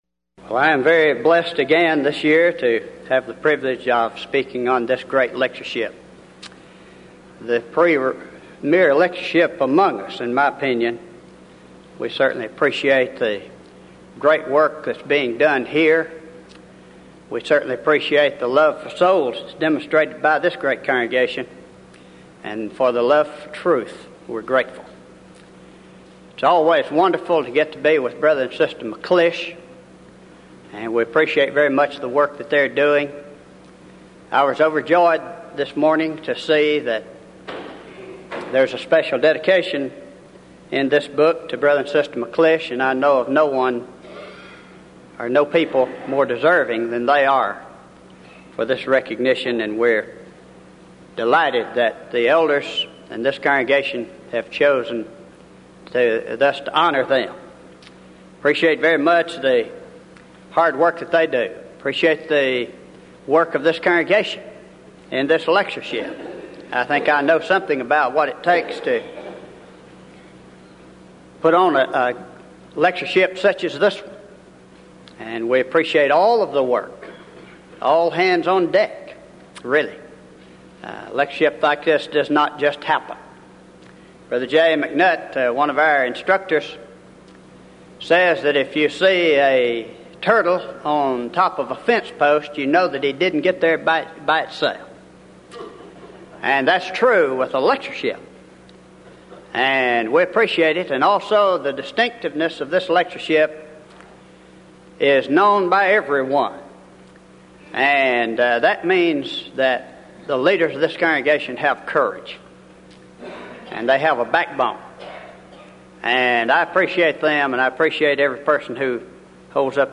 1996 Denton Lectures
lecture